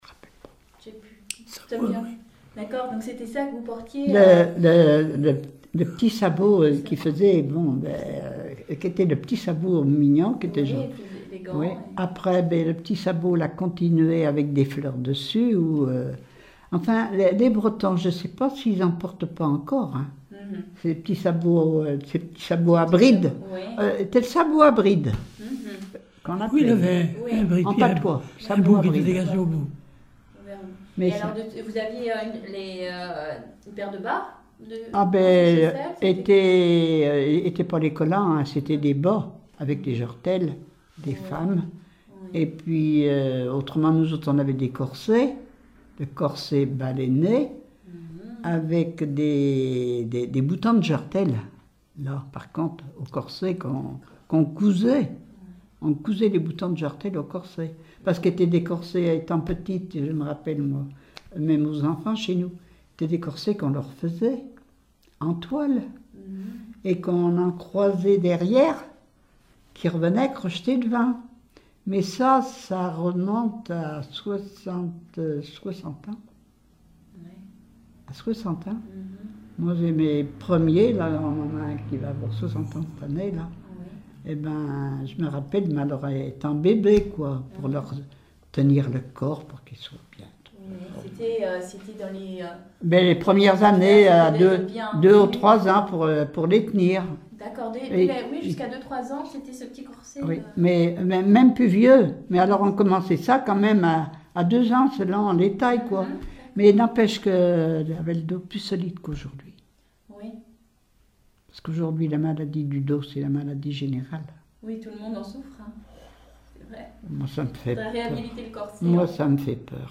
Témoignage sur la vie de l'interviewé(e)
Catégorie Témoignage